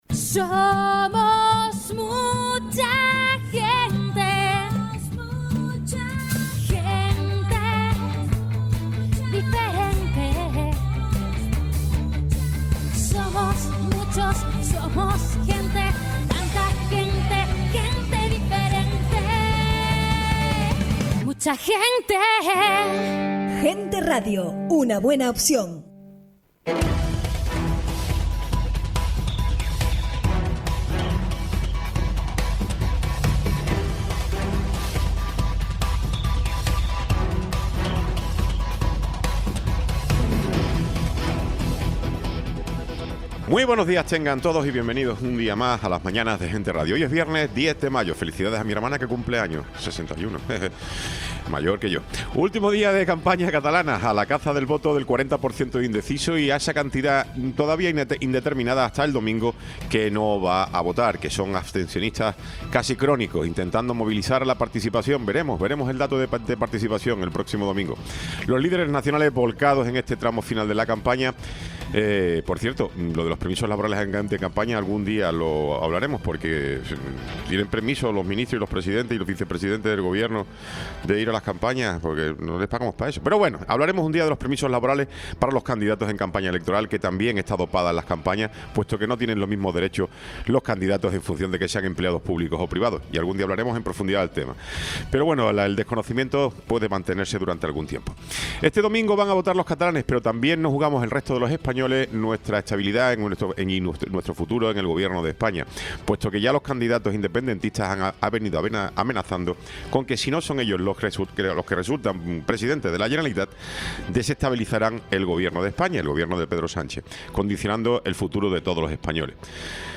Tiempo de entrevista
Programa sin cortes